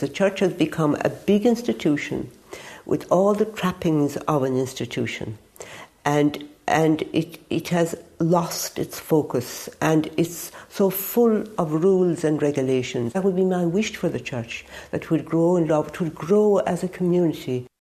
She spoke previously about how she felt the Catholic Church has lost its way…………